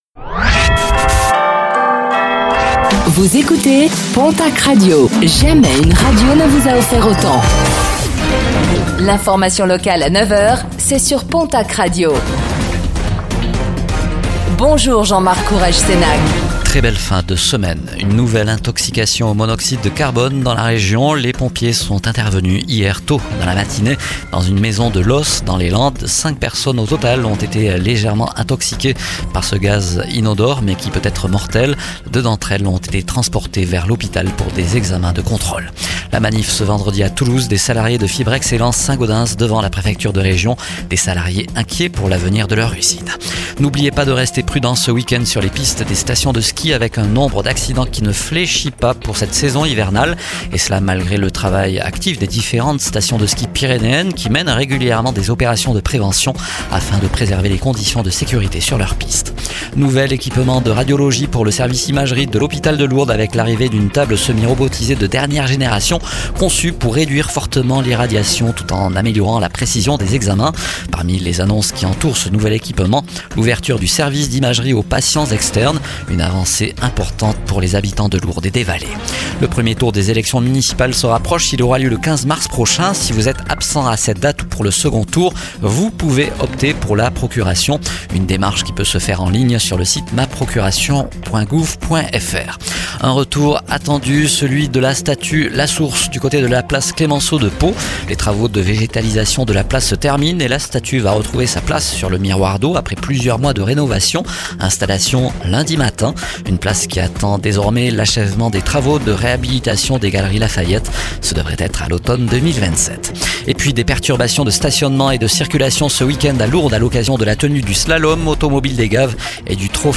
Réécoutez le flash d'information locale de ce vendredi 06 mars 2026